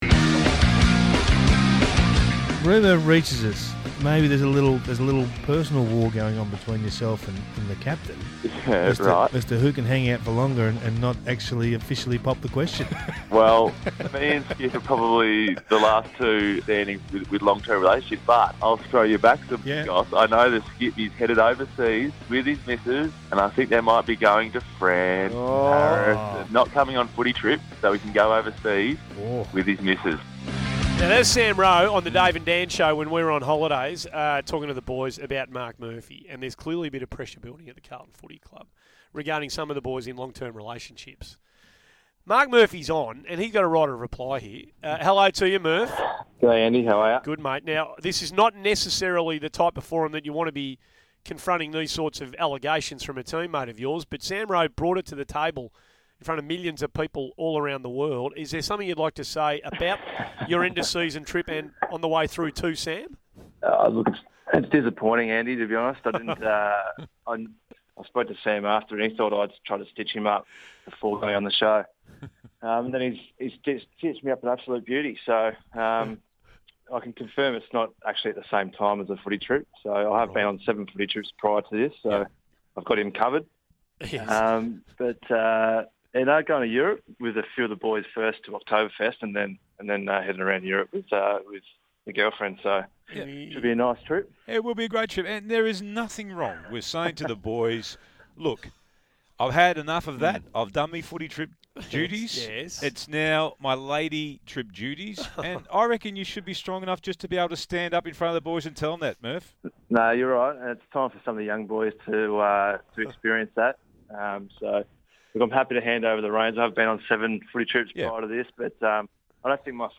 Carlton captain speaks to SEN 1116's Morning Glory ahead of his 200th game.